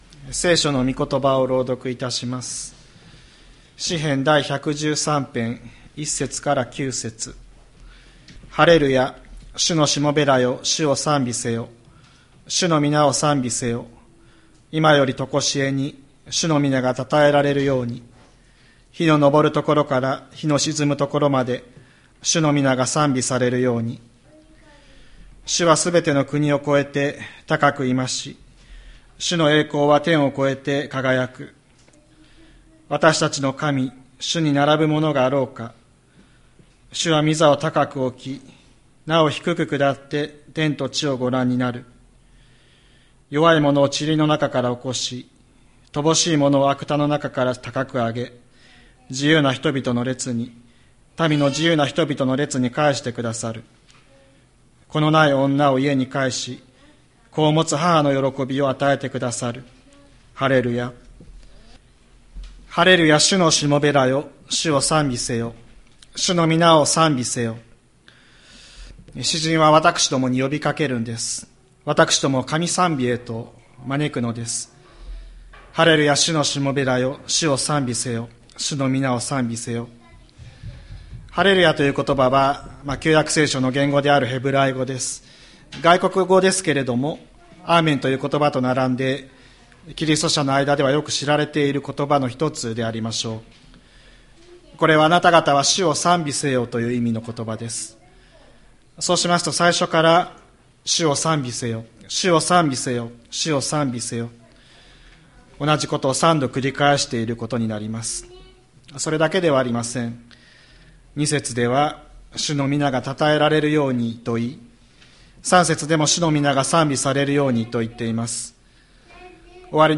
2024年12月01日朝の礼拝「低きにくだる神」吹田市千里山のキリスト教会
千里山教会 2024年12月01日の礼拝メッセージ。